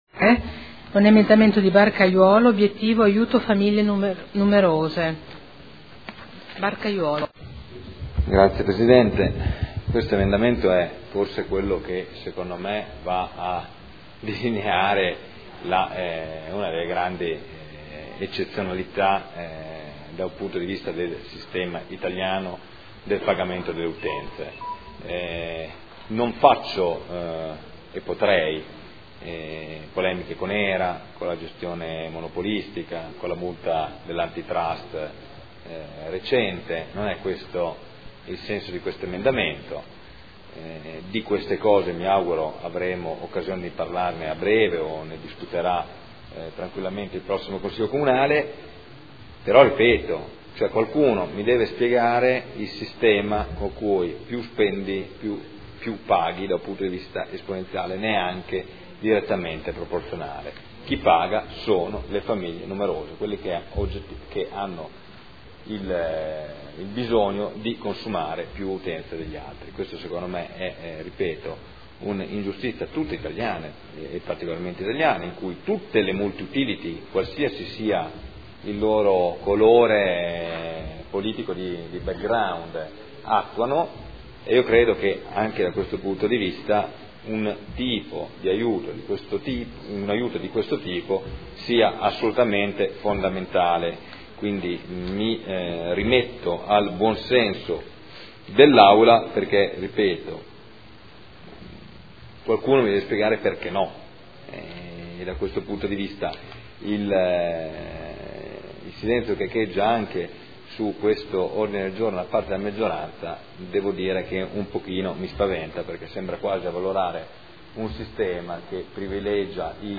Seduta del 13 marzo. Dichiarazioni di voto su singoli emendamenti o complessive